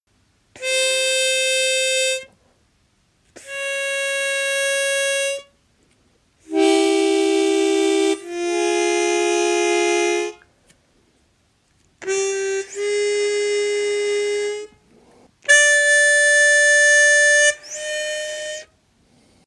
Hohner “Up To Date” chromatically tuned
Based on these samples, it is pretty clear to me that the notes order consist of  a C and a C# (slide pushed in) scale.